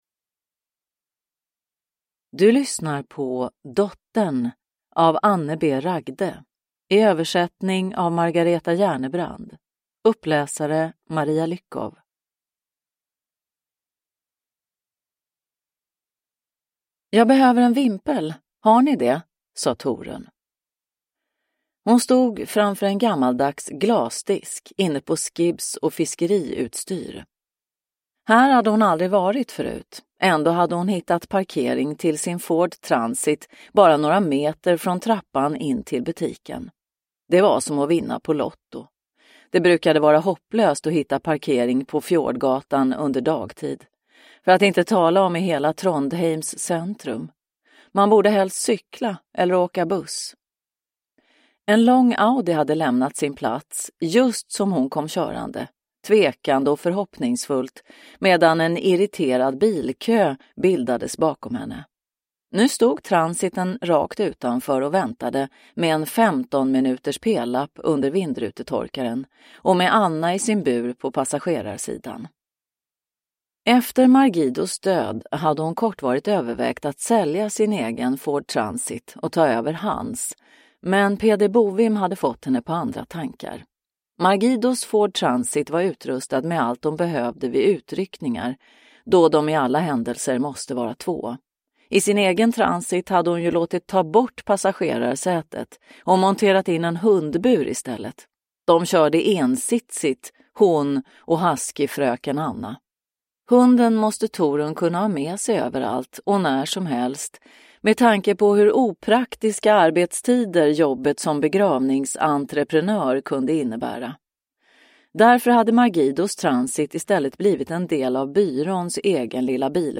Dottern – Ljudbok – Laddas ner